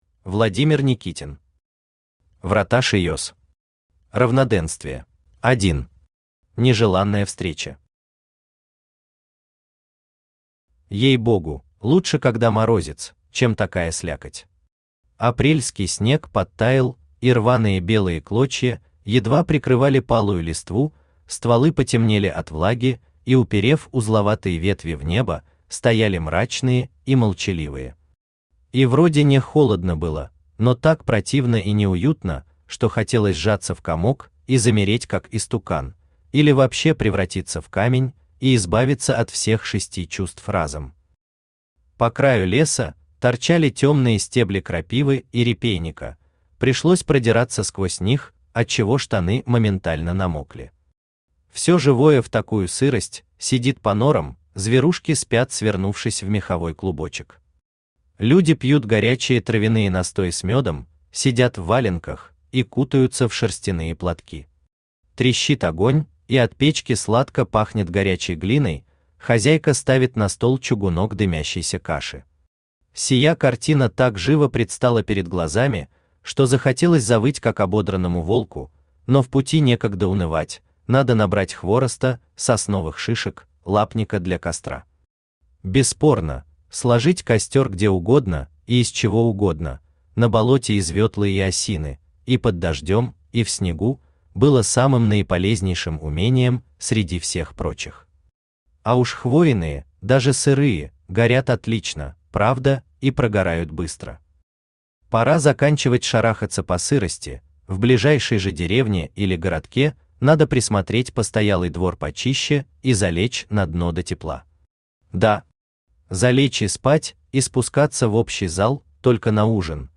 Аудиокнига Врата Шиес. Равноденствие | Библиотека аудиокниг
Aудиокнига Врата Шиес. Равноденствие Автор Владимир Никитин Читает аудиокнигу Авточтец ЛитРес. Прослушать и бесплатно скачать фрагмент аудиокниги